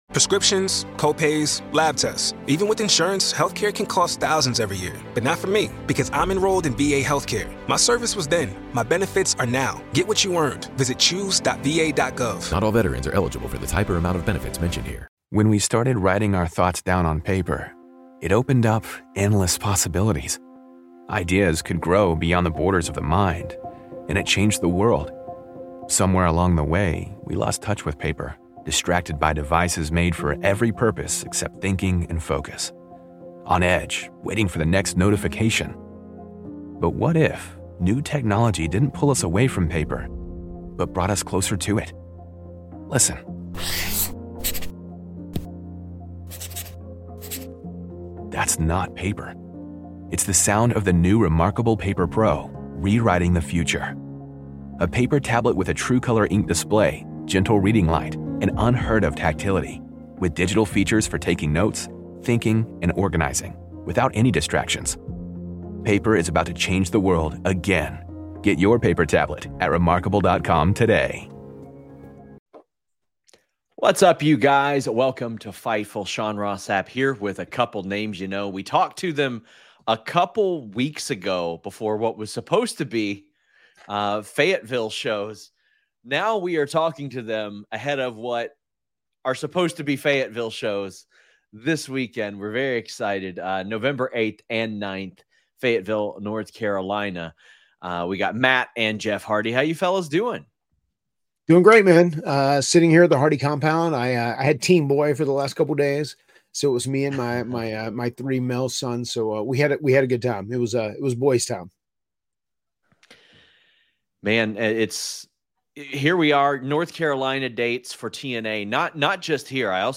Hardy Boyz: The Hardest They've Been Hit, Erik Watts Inspiration?, Chris Bey | Interview | Fightful News